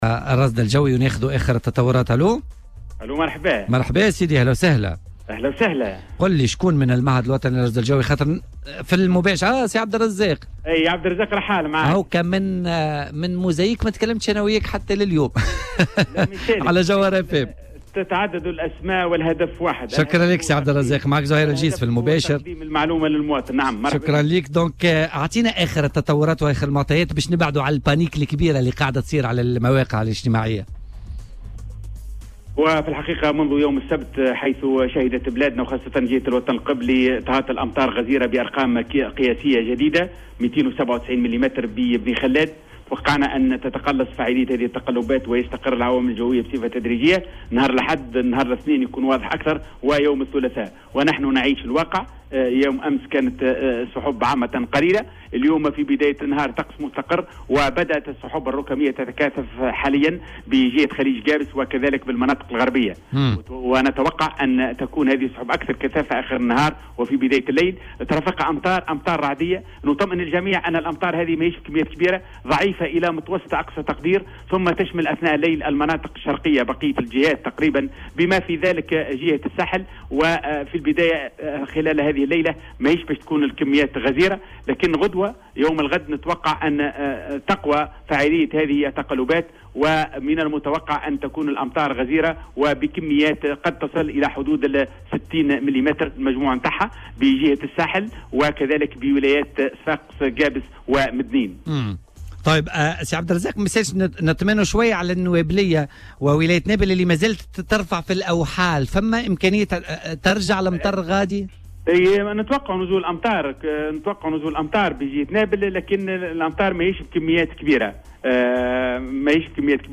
في مداخلة هاتفية مع "بوليتيكا" على "الجوهرة أف أم"